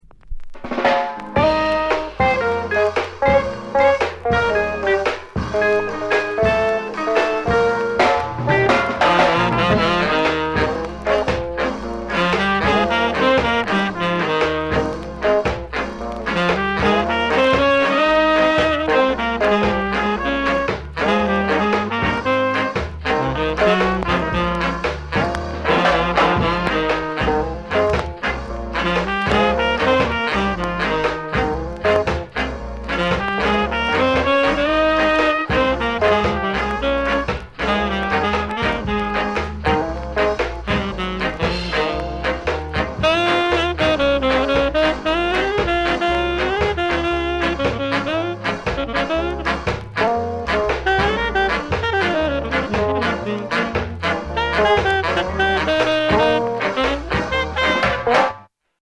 GREAT SKA INST